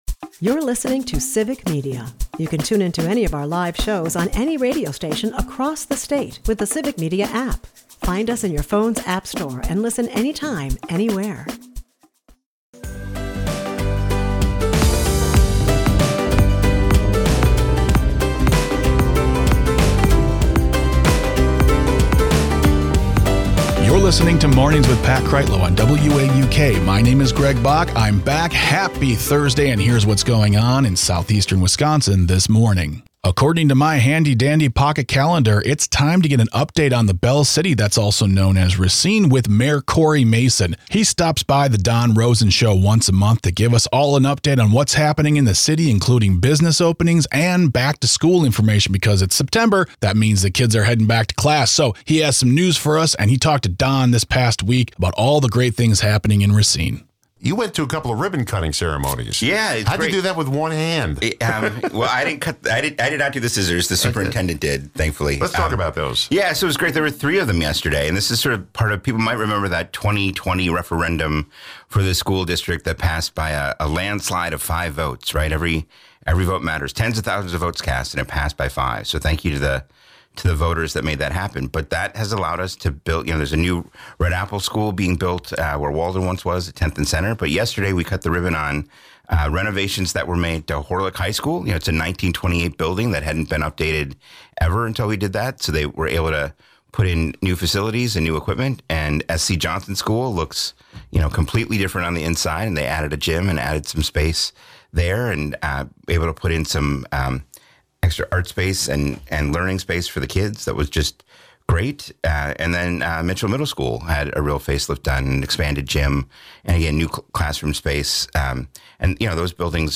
We wrap things up on location at The Cactus Club
WAUK Morning Report is a part of the Civic Media radio network and air four times a morning.